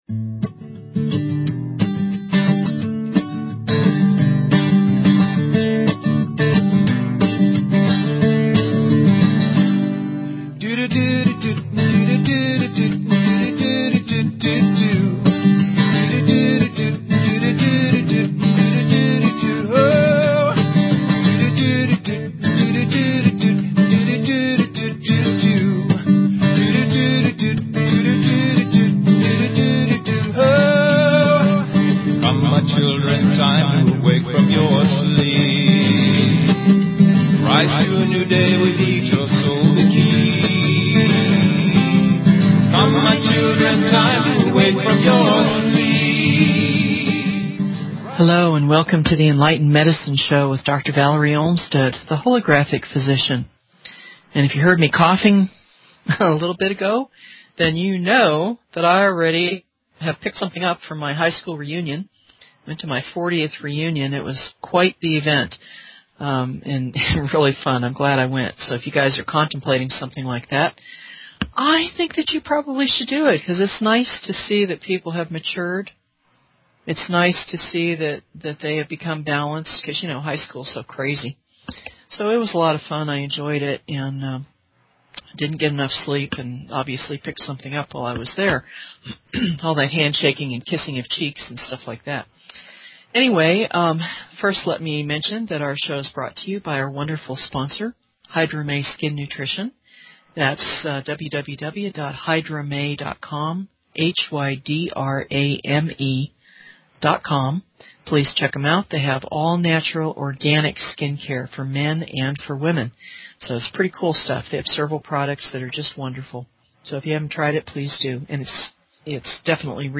Talk Show Episode, Audio Podcast, Enlightened_Medicine and Courtesy of BBS Radio on , show guests , about , categorized as
Tune in for a musical treat!